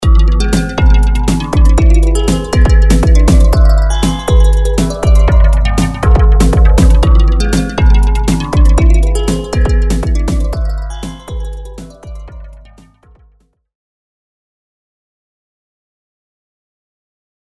前回に引き続き、パーカッシブなエスニック系の音色の作成方法とアルペジエーターの設定について紹介していきます。
＜デモサウンド＞
今回の音色はアルペジエーター機能をStepモードで使用してシーケンスを演奏させています。フレーズは7/8拍子、1小節のループフレーズで、実用音域内でオクターブを上下してフレーズが聴き比べられるようにしました。
また、より演奏感がわかるように、フレーズに合わせてSpireのファクトリーバンク8番のドラム音色のプリセットにあるキック、スネア、ハイハットを組み合わせてドラムループのトラックを併せて作成しています。
ポリフォニック・シンセサイザー